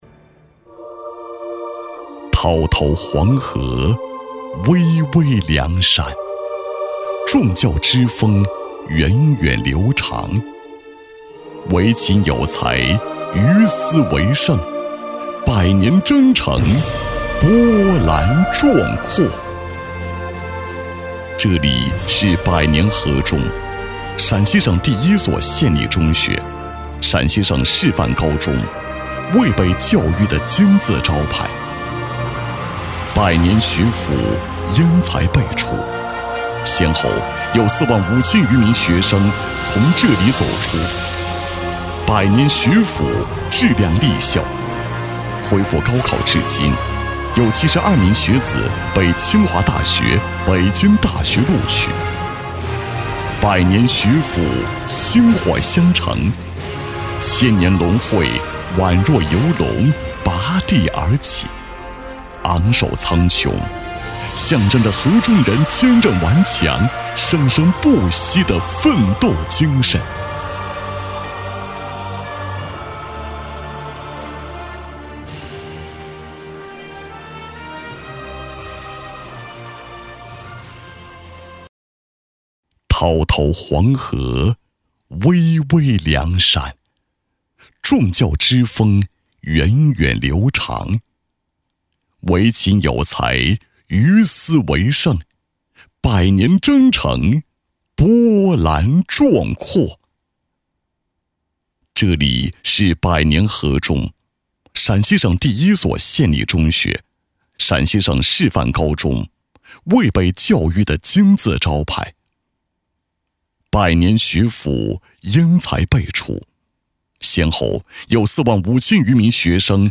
男123-勇立潮头奋楫行-大气深情